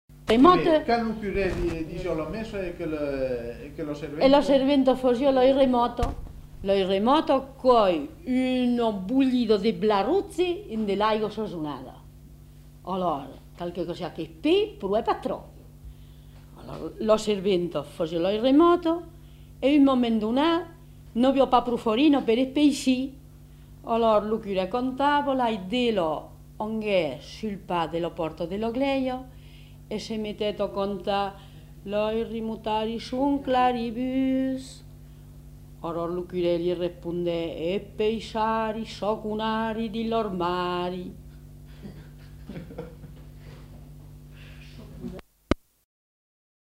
Lieu : Castels
Genre : conte-légende-récit
Type de voix : voix de femme
Production du son : parlé